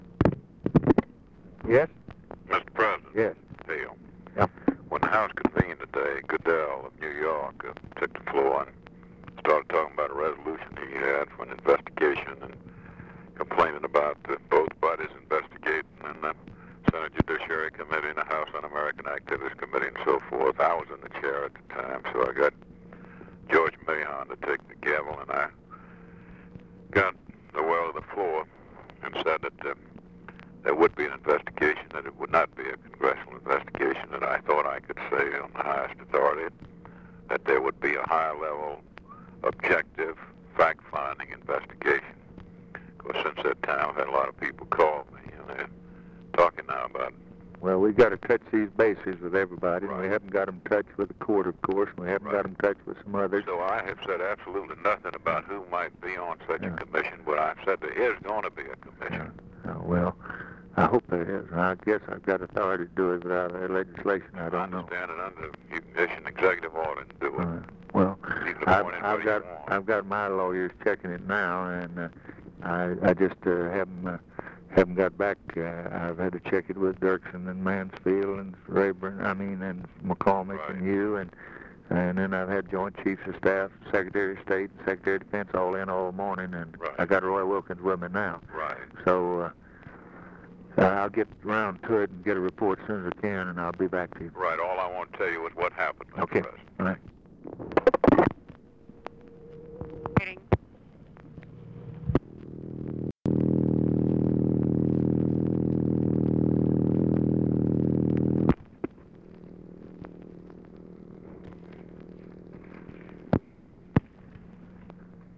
Conversation with HALE BOGGS, November 29, 1963
Secret White House Tapes